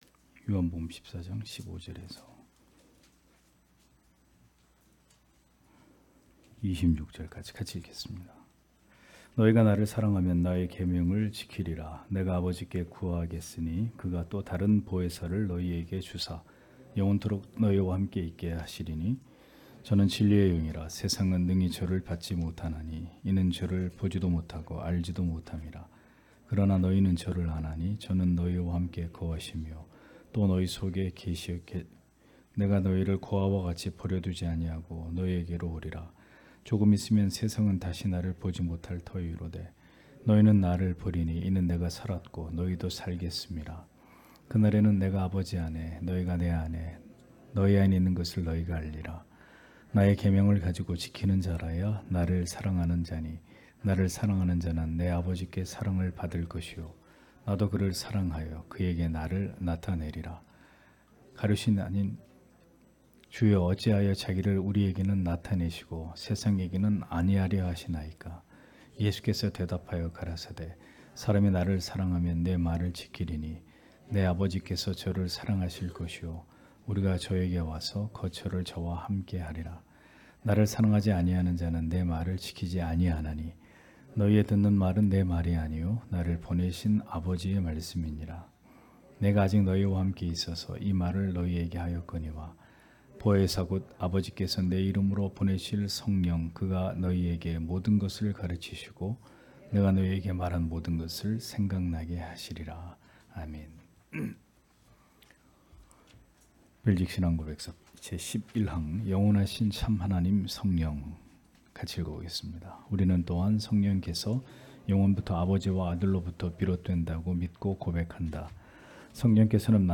주일오후예배 - [벨직 신앙고백서 해설 11] 제11항 영원하신 참 하나님 성령(요 14:15-26)